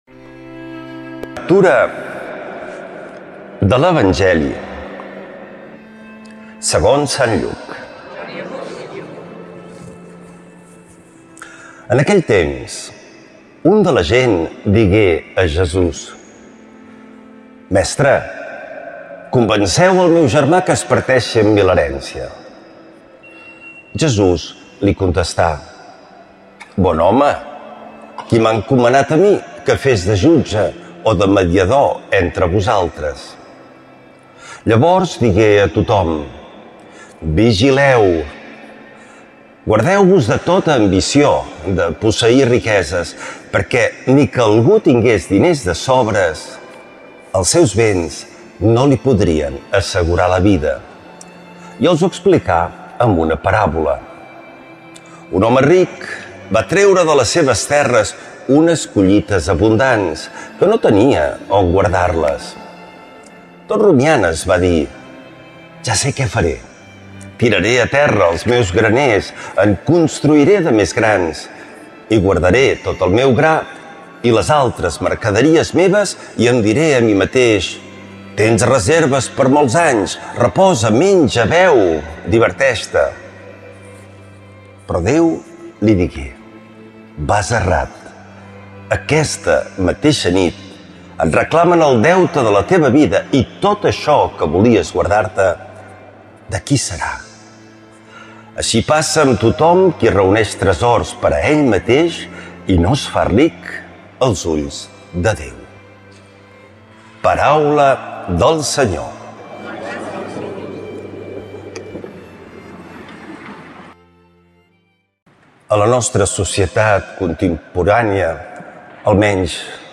L’Evangeli i el comentari de diumenge 03 d’agost del 2025.
Lectura de l’evangeli segons sant Lluc